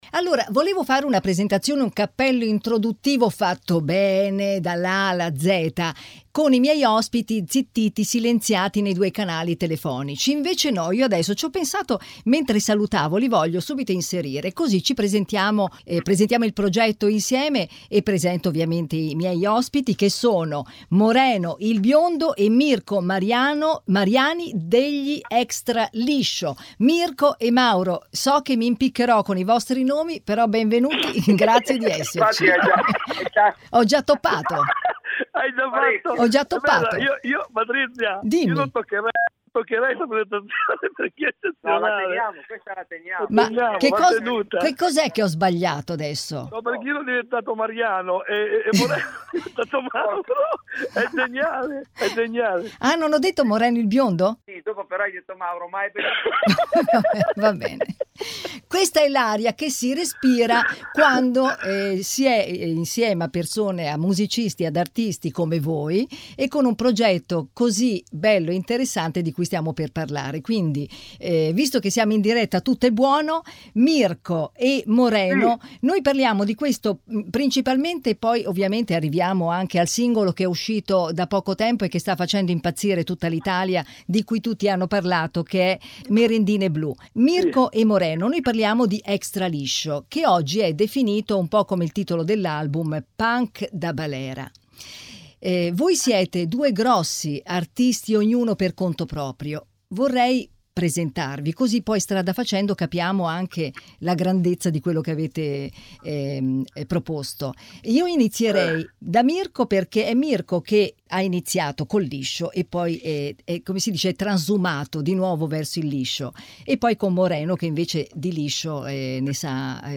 Home Magazine Interviste EXTRALISCIO, Punk da Balera: un fenomeno tutto italiano